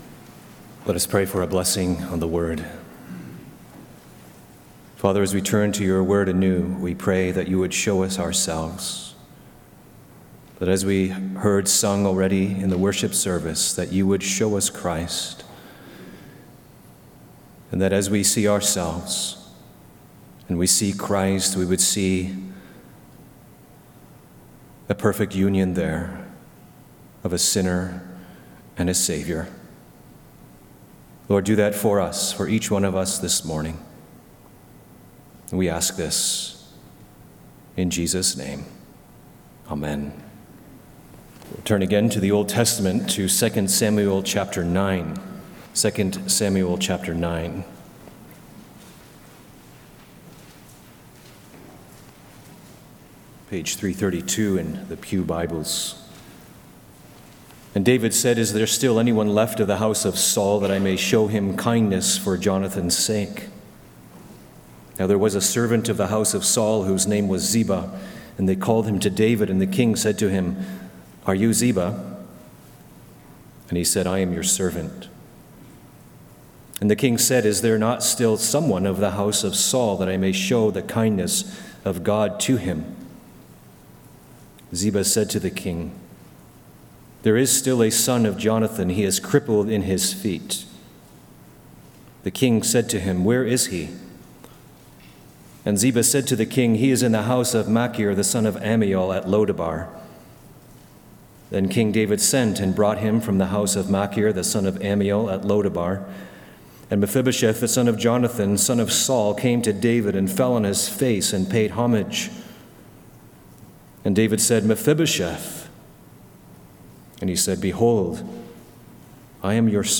Various Sermons